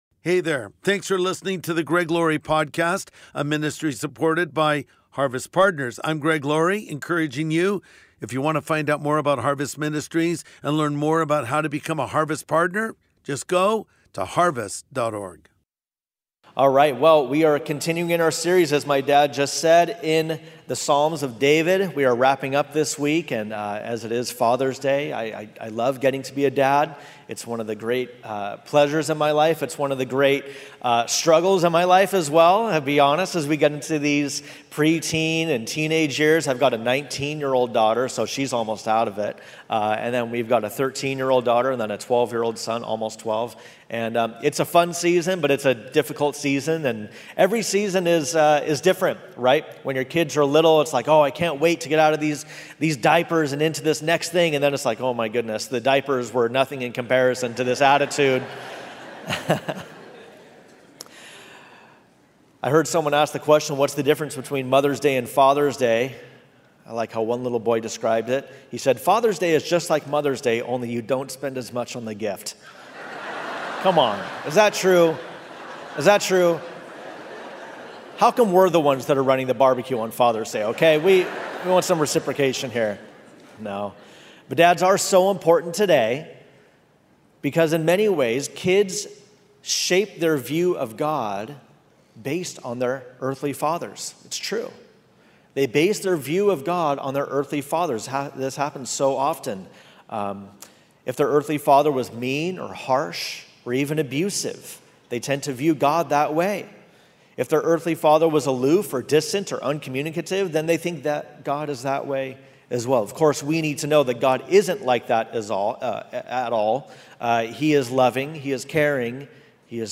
A Father's Love | Sunday Message